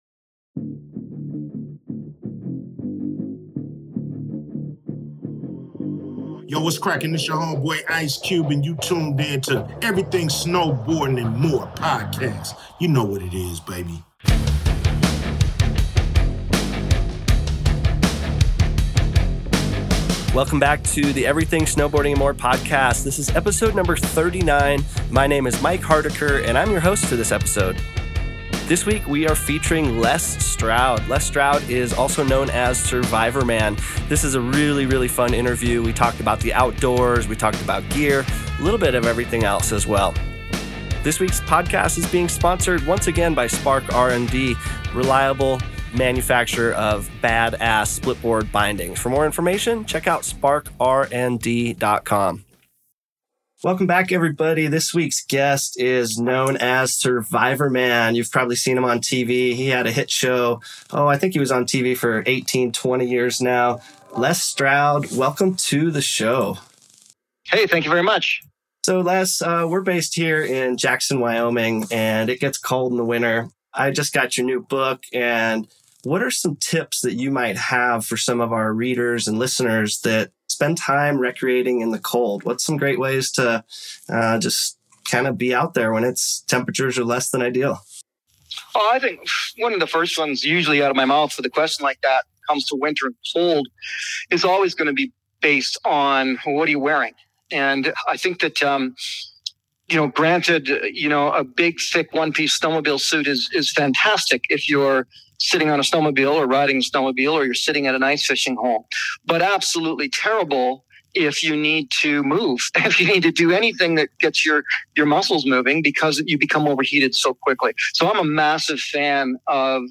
Les Stroud Interview - Star of TV Series Survivorman